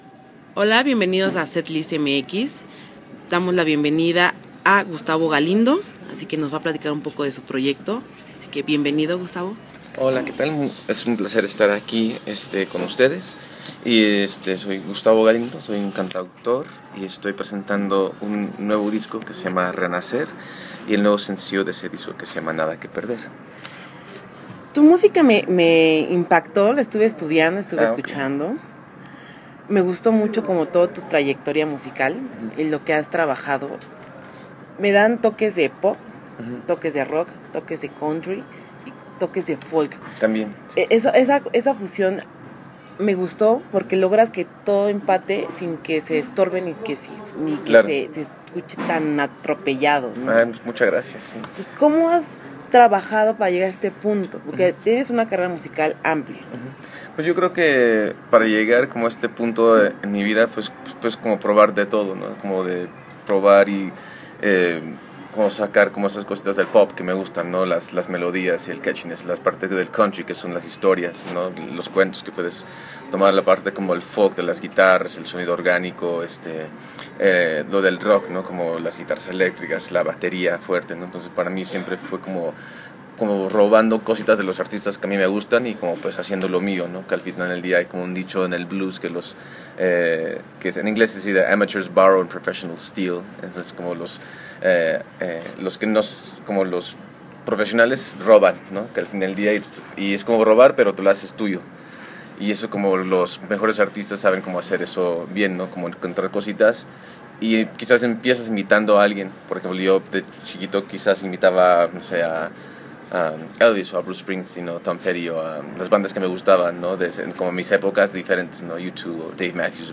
Les dejamos la plática que tuvimos con él y también pueden checar el vídeo en la parte de abajo. http